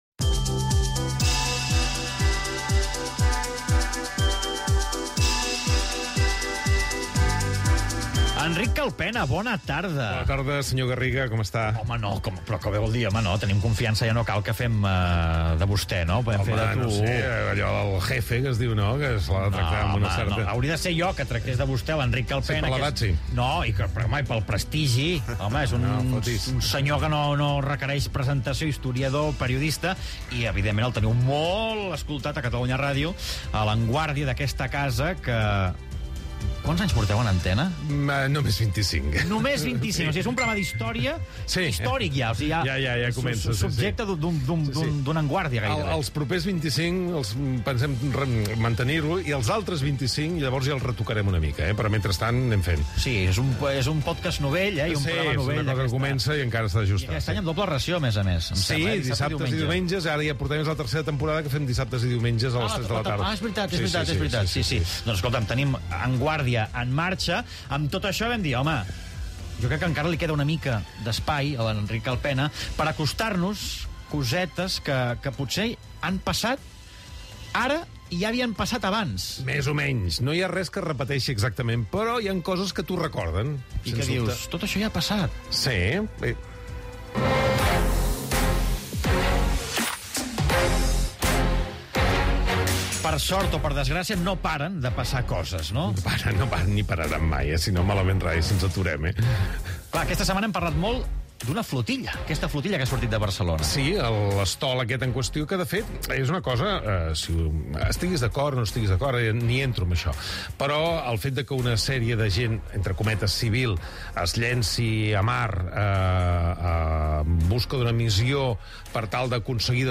La Global Sumud Flotilla que navega cap a Gaza. Enquesta a la ciutadania i recull històric d'altres "flotilles"